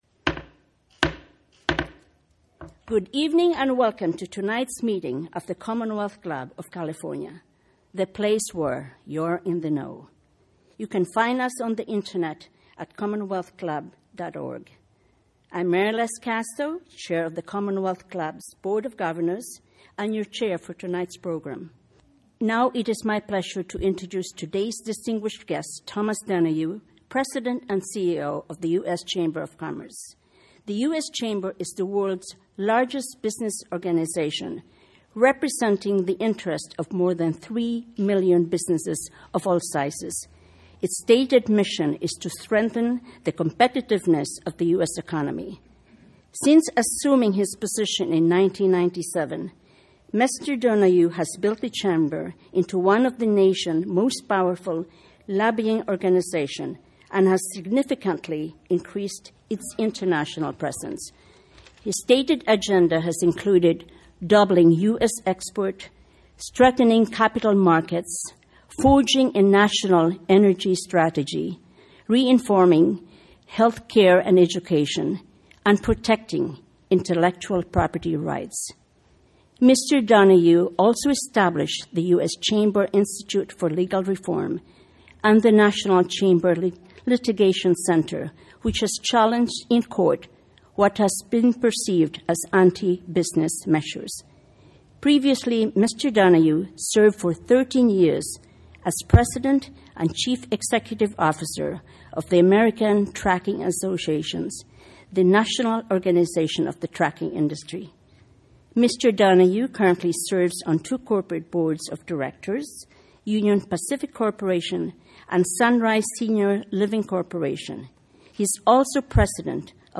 He will offer a business perspective on the 2012 elections and discuss the role his organization plans to play in the national dialogue. Location: SF Club Office Times:5:30 p.m. check-in, 6 p.m.